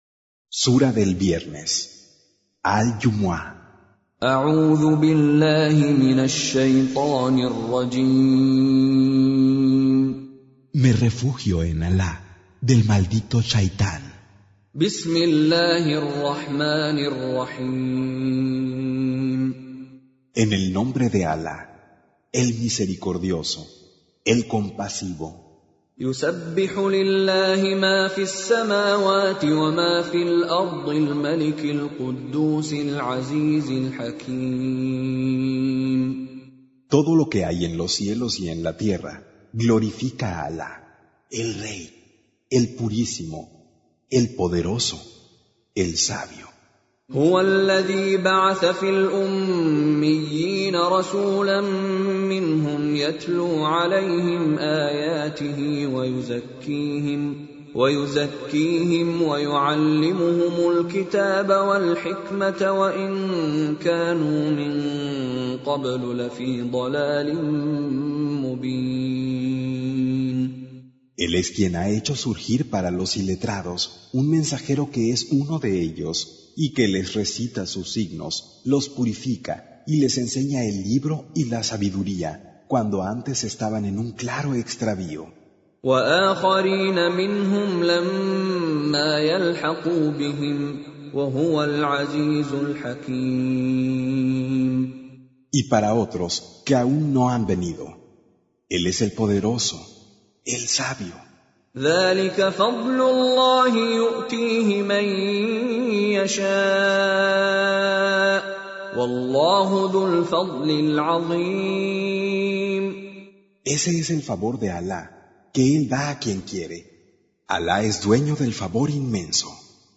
Con Reciter Mishary Alafasi
Surah Sequence تتابع السورة Download Surah حمّل السورة Reciting Mutarjamah Translation Audio for 62. Surah Al-Jumu'ah سورة الجمعة N.B *Surah Includes Al-Basmalah Reciters Sequents تتابع التلاوات Reciters Repeats تكرار التلاوات